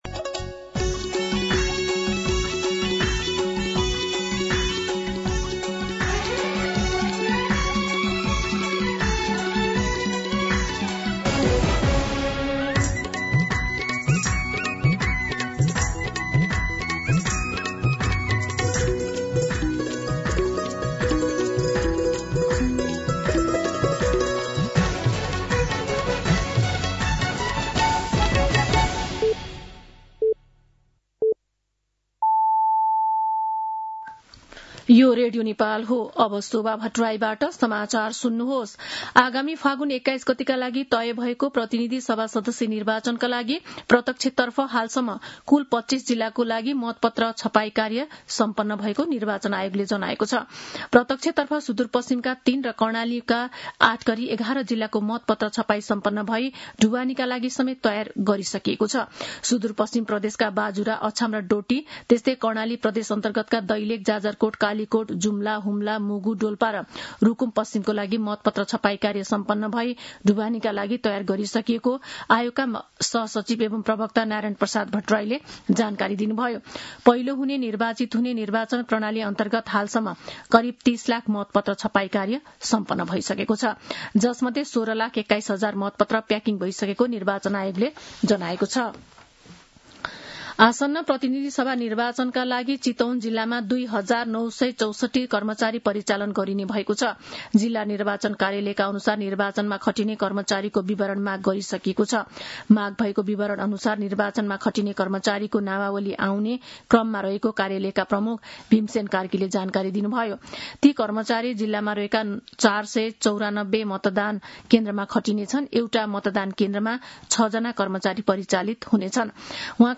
दिउँसो ४ बजेको नेपाली समाचार : १८ माघ , २०८२